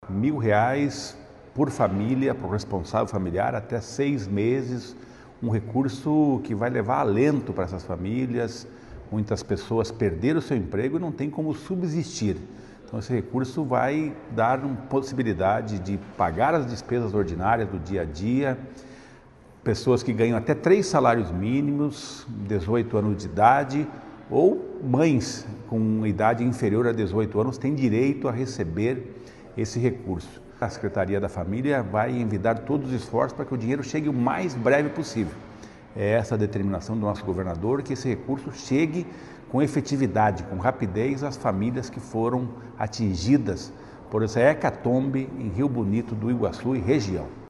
Sonora do secretário do Desenvolvimento Social e Família, Rogério Carboni, sobre o início dos pagamentos do auxílio de R$ 1 mil para moradores de Rio Bonito do Iguaçu já nesta quinta